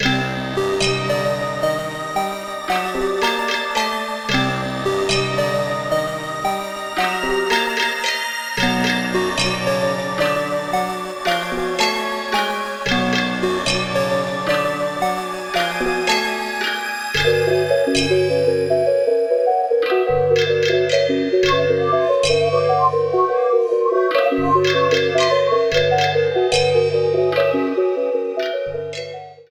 trimmed to 29.5 seconds and faded out the last two seconds